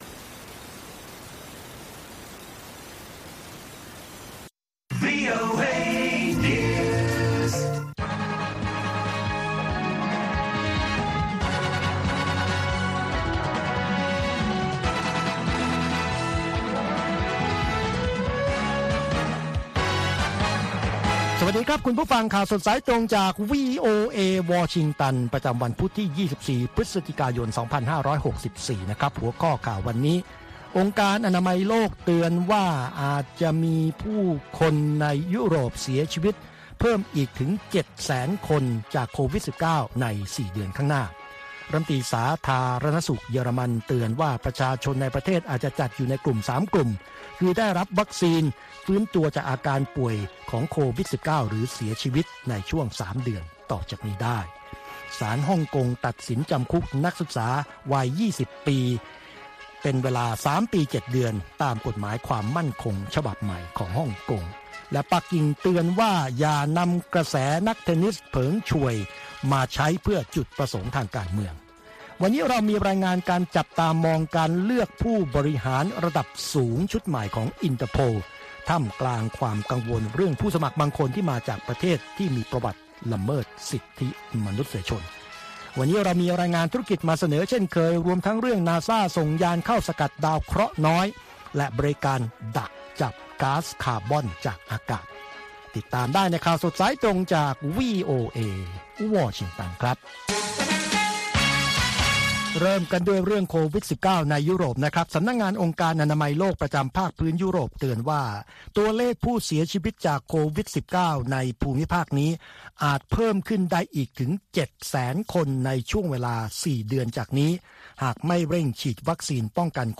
ข่าวสดสายตรงจากวีโอเอ วันพุธ ที่ 24 พฤศจิกายน 2564